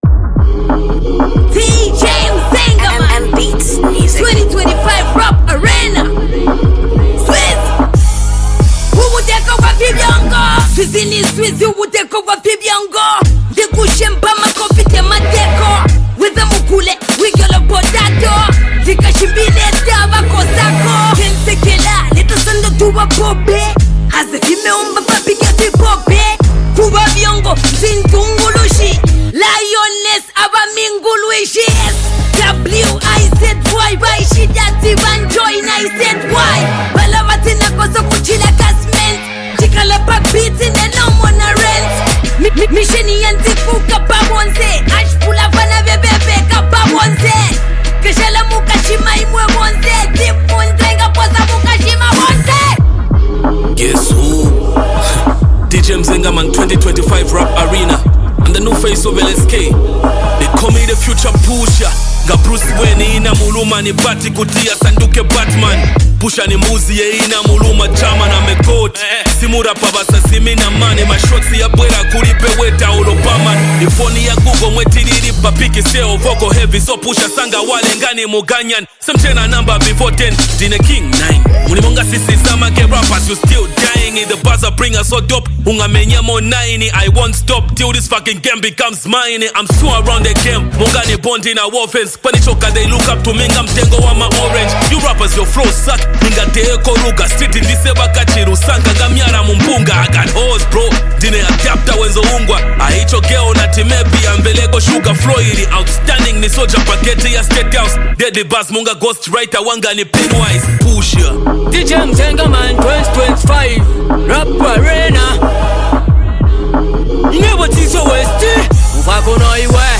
powerful, high-energy cypher-style project
Zambian hip-hop